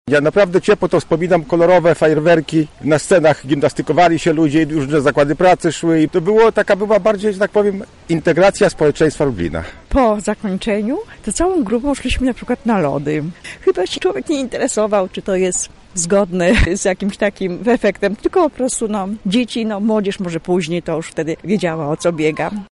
Jak lublinianie wspominają obchody Święta Pracy? [SONDA]
Zapytaliśmy mieszkańców Lublina o wspomnienia związane z tamtym okresem:
sonda